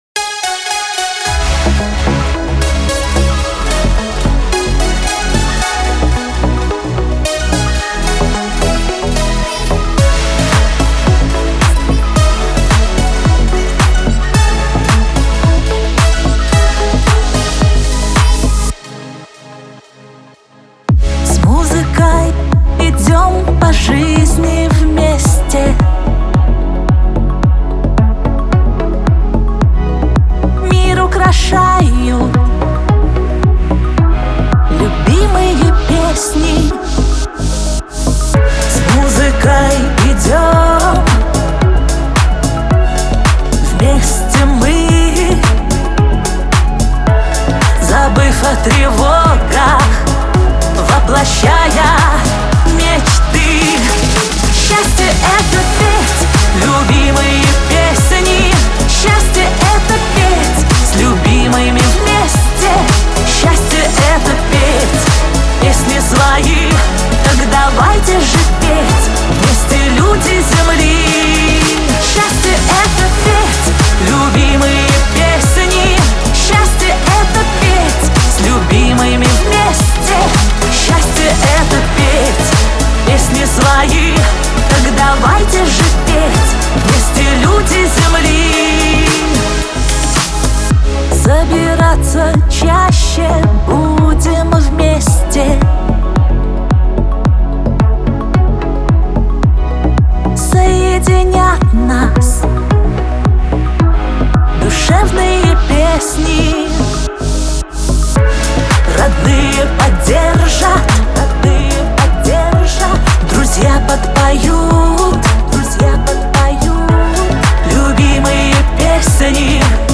Веселая музыка
Лирика